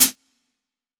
013_Lo-Fi Dry Hi-Hat.wav